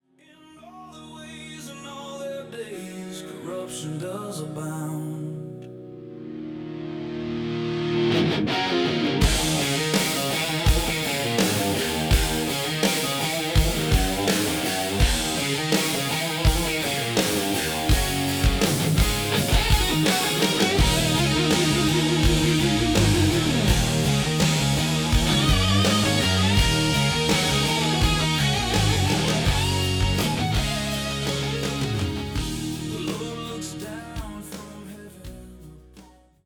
Raw honesty building to redemptive hope
Heavy electric guitar riffs and soaring solos
Driving bass lines that anchor each song
Distinctive rock drumming with dynamic fills
Raw, authentic Southern rock vocals